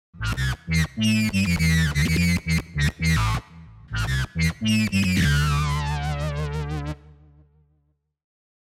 VSTi gitarový syntetizér
Ovladaju sa priamo audio signalom z gitary.
synth3.mp3